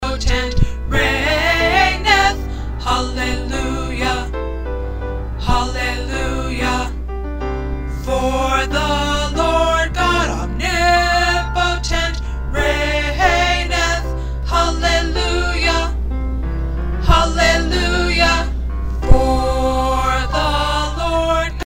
The 1st tenors are singing a G and the 2nd tenors sing an E.